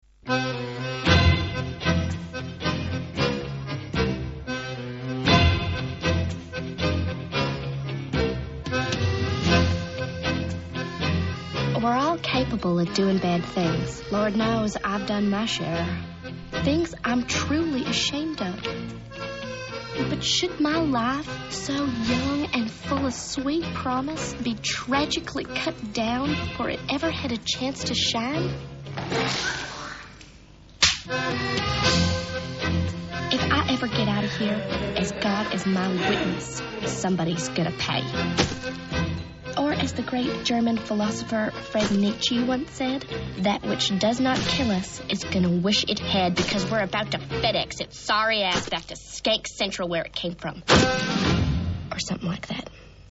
paid lipservice by the use of accordion
Tango theme.